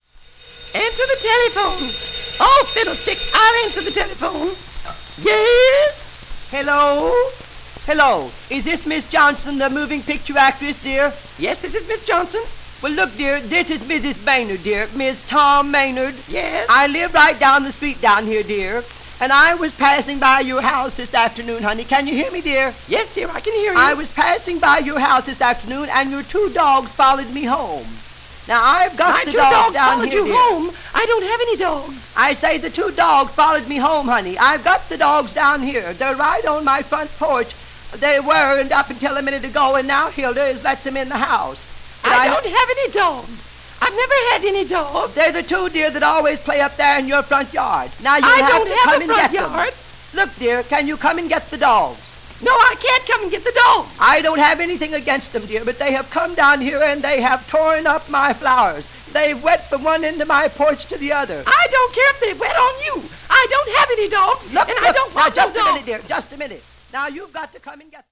He had a trick voice that sounded exactly like an old woman from Arkansas – the accent and everything were all just perfect.